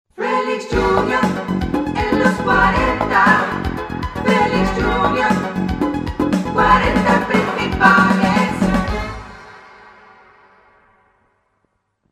"Jingle"